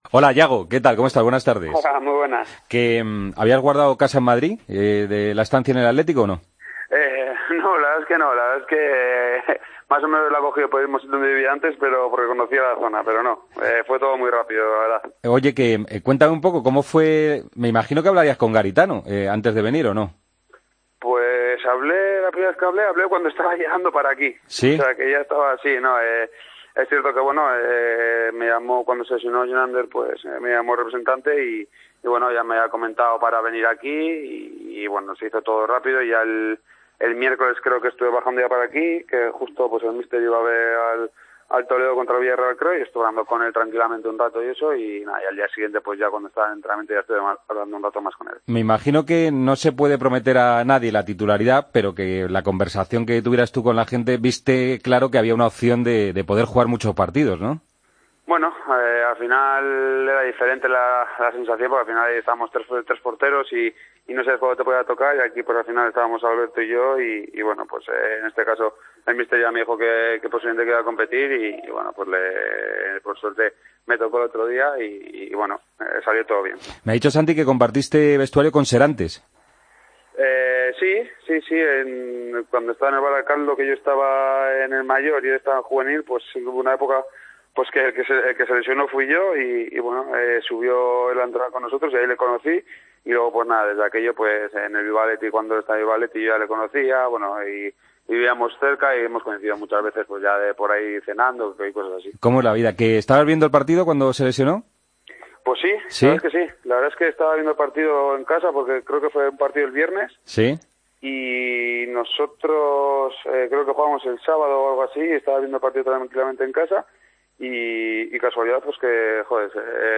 El nuevo guardameta del Leganés pasa por Deportes COPE para contarnos cómo está el club pepinero.